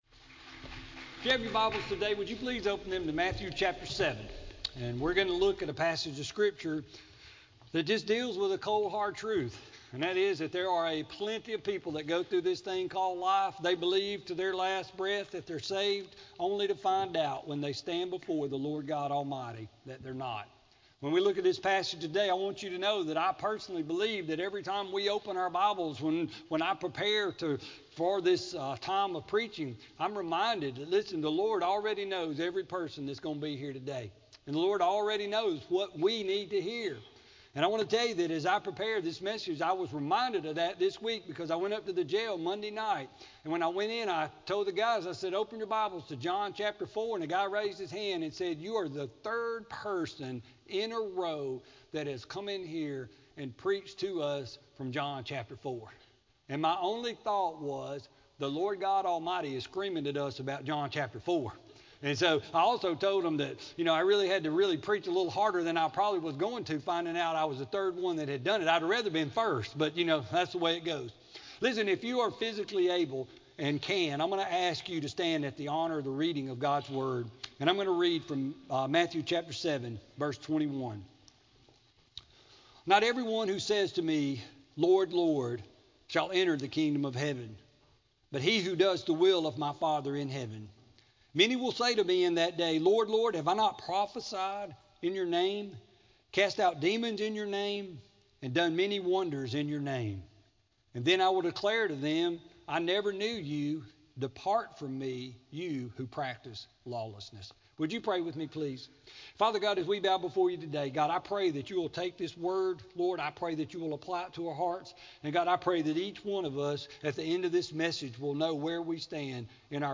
Sermon-1-17-16-CD.mp3